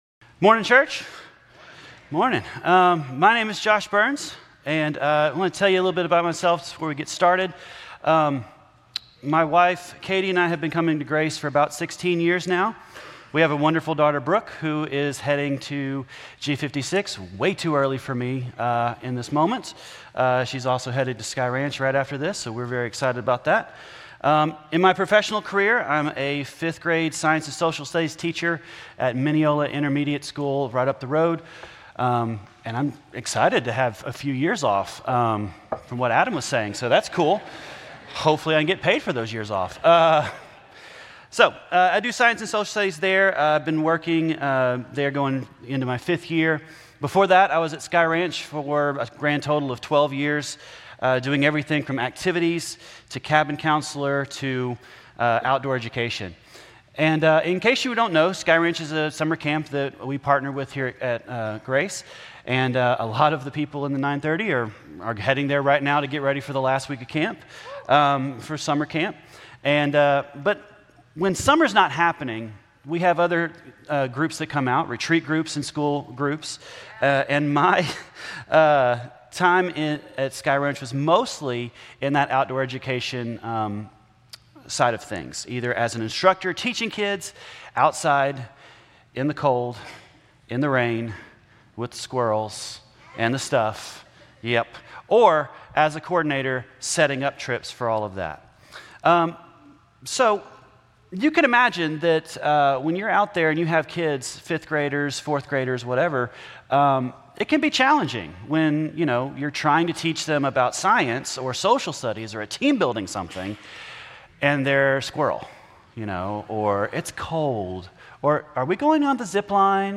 Grace Community Church Lindale Campus Sermons 8_3 Lindale Campus Aug 04 2025 | 00:32:33 1x 00:00 / 00:32:33 Subscribe Share RSS Feed Share Link Embed